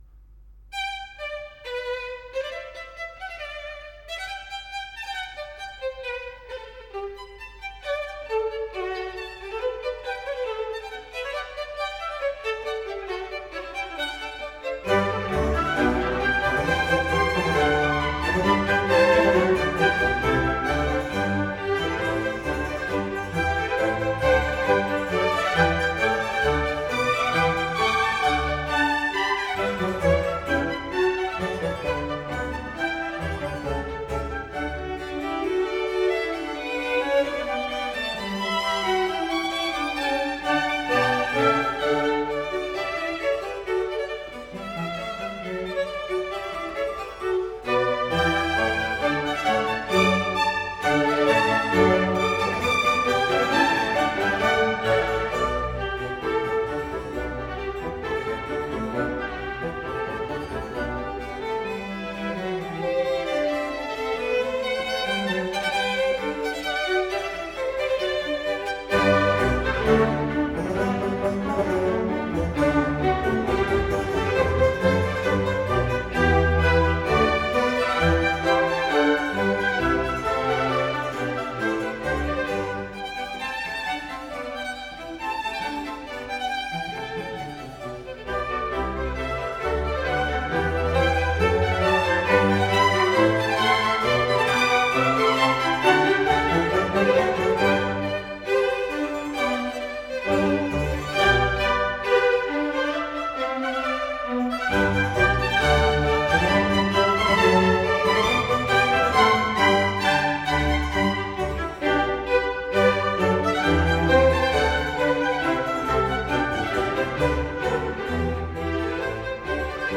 in G major - Allegro